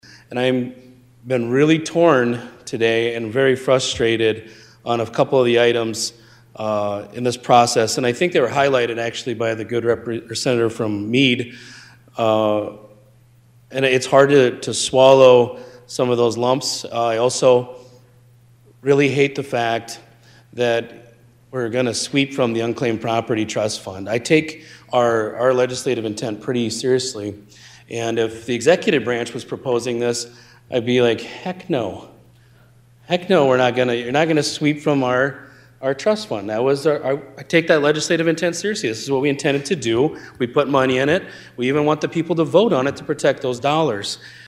SD Senate: